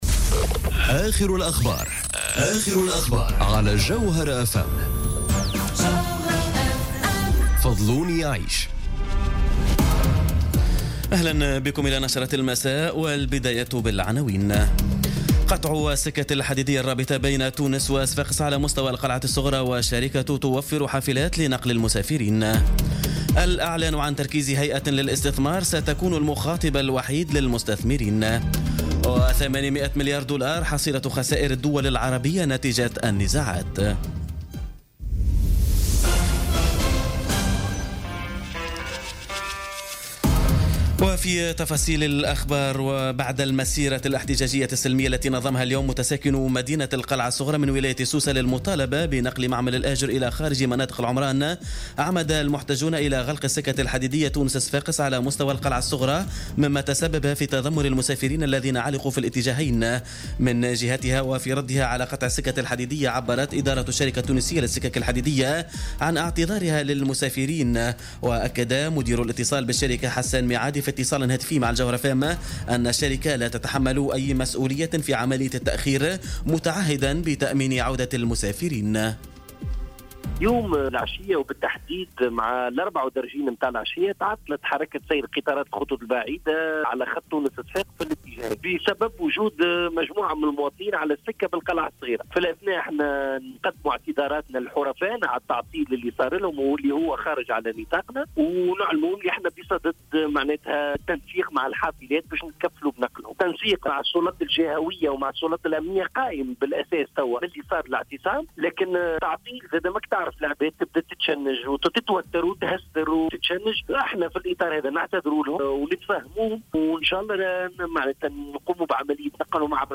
نشرة أخبار السابعة مساءً ليوم السبت 18 نوفمبر 2017